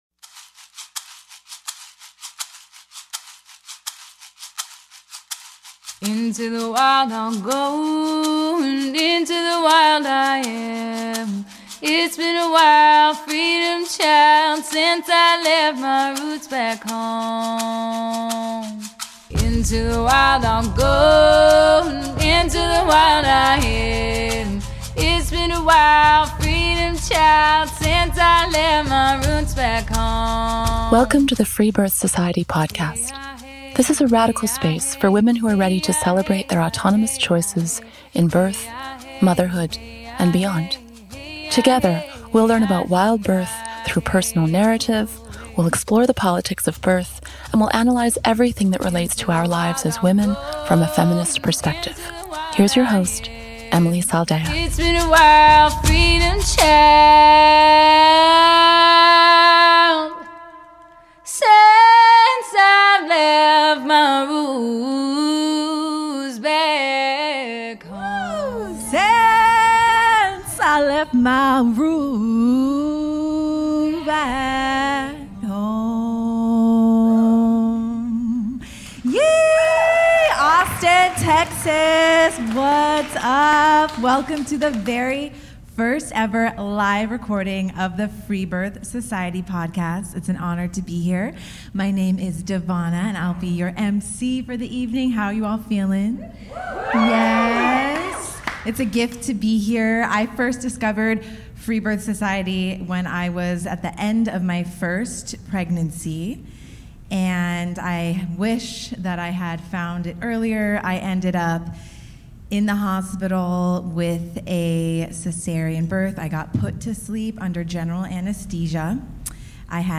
Live Show in Austin, Texas